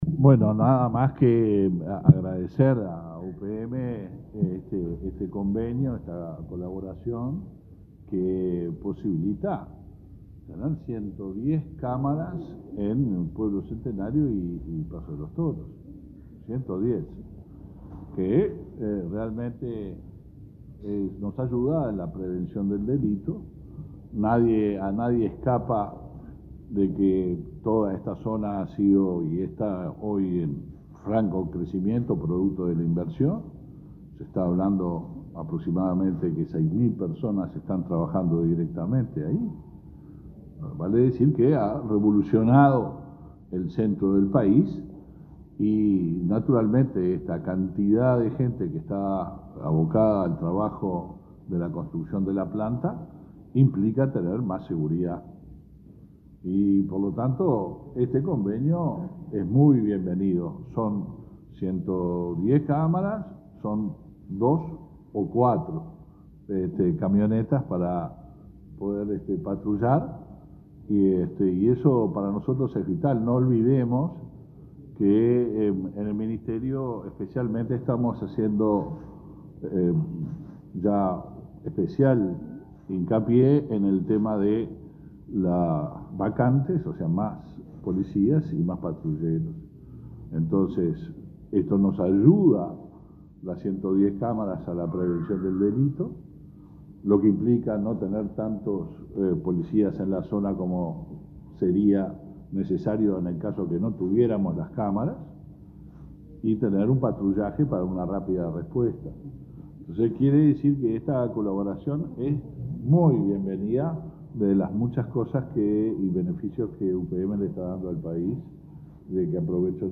Oratoria del ministro del Interior, Luis Alberto Heber